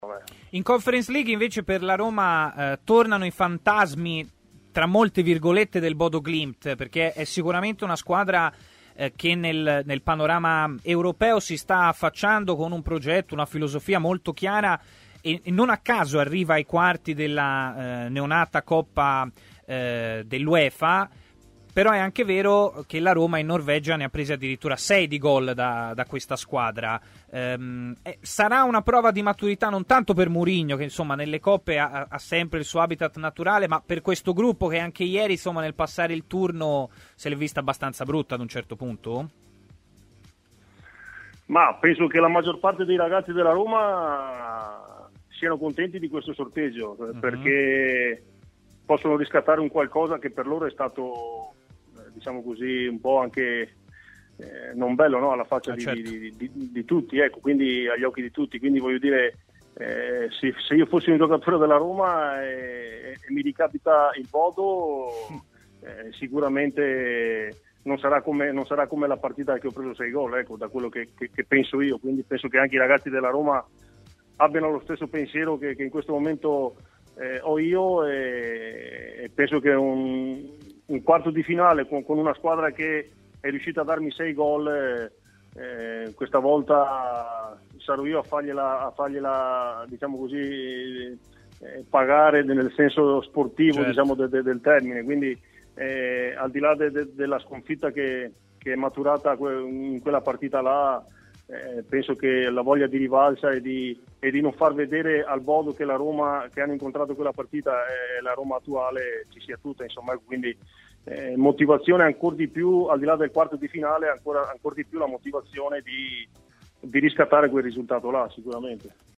Ospite di Stadio Aperto, sulle frequenze di TMW Radio, Filippo Maniero, ex attaccante tra le altre di Verona, Milan e Venezia, ha parlato dei temi caldi legati alla Serie A e, quindi, anche della Roma.